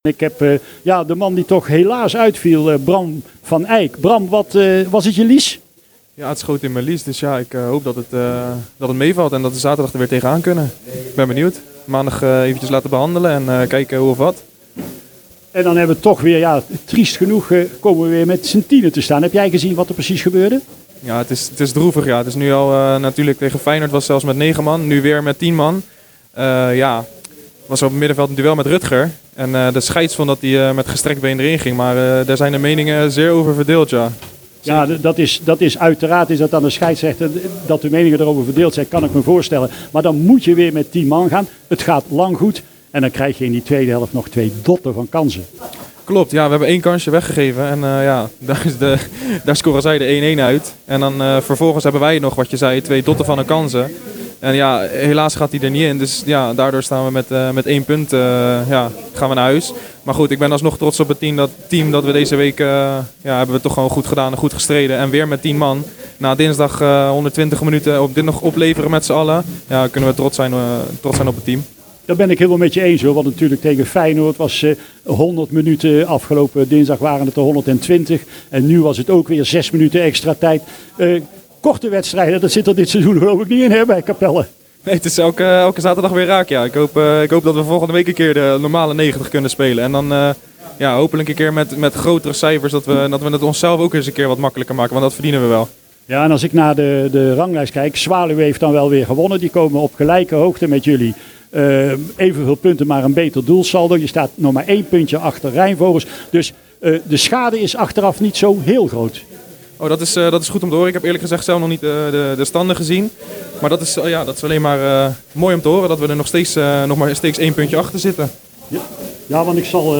met de microfoon op pad om spelers te interviewen.